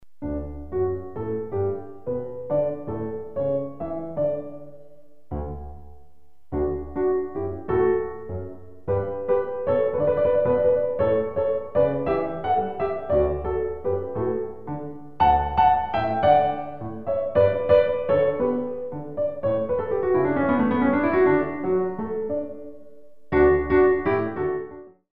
All piano CD for Pre- Ballet classes.